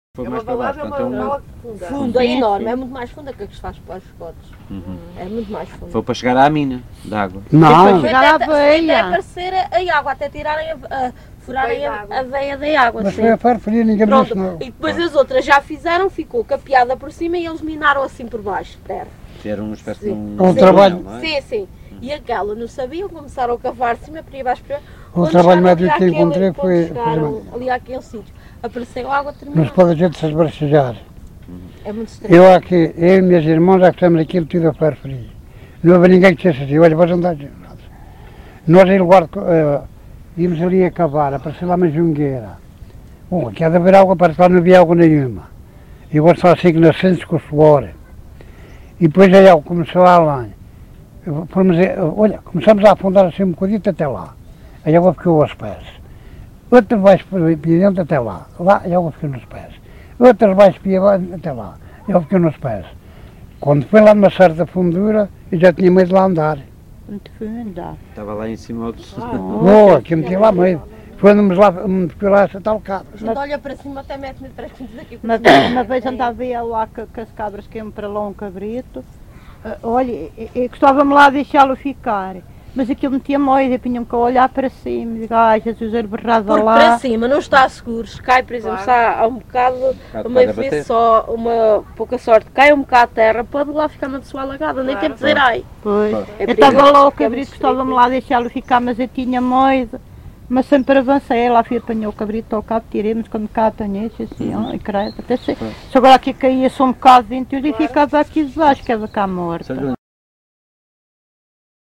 LocalidadeUnhais da Serra (Covilhã, Castelo Branco)